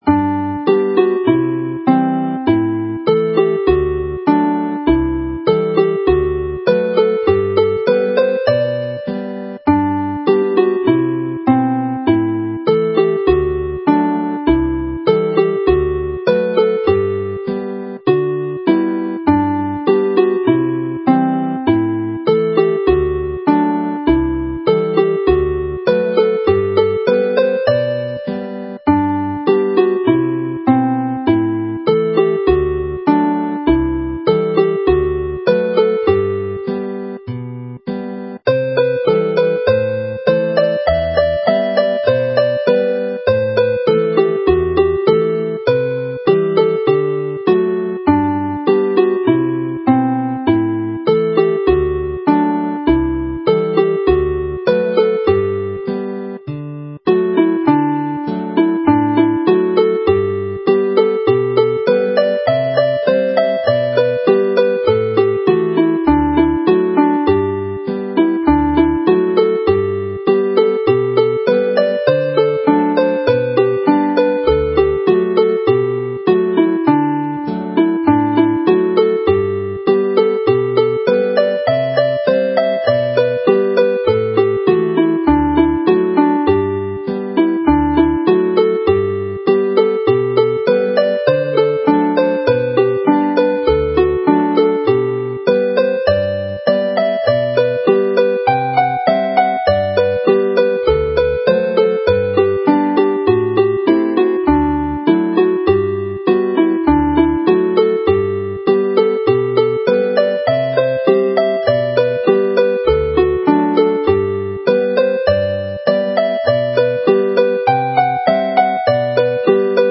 Chwarae'r set yn araf
Play the set slowly